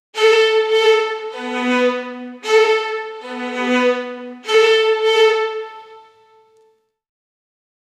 Create swish ispirated by argentinian tango with violin and bandoneon
create-swish-ispirated-by-j726gmxz.wav